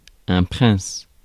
Prononciation
Synonymes altesse légende Prononciation France: IPA: [pʁɛ̃s] Le mot recherché trouvé avec ces langues de source: français Traduction Substantifs 1.